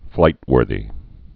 (flītwûrthē)